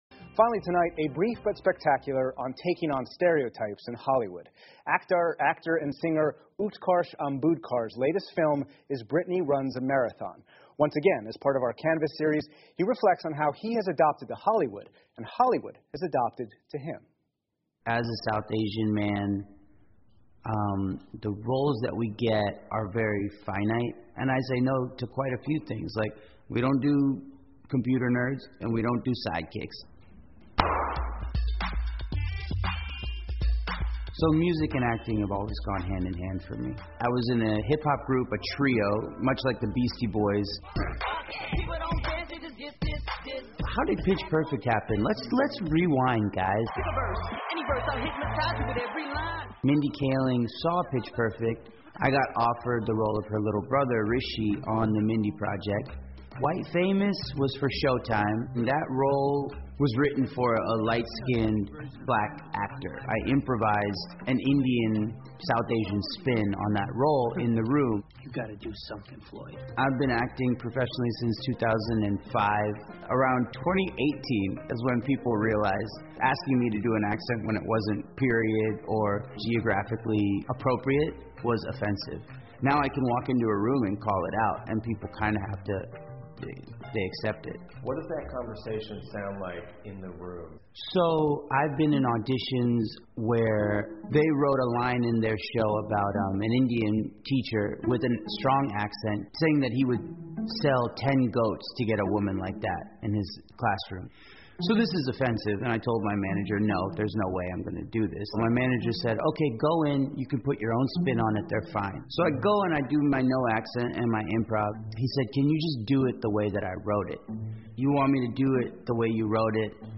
PBS高端访谈:美国男演员和好莱坞的故事 听力文件下载—在线英语听力室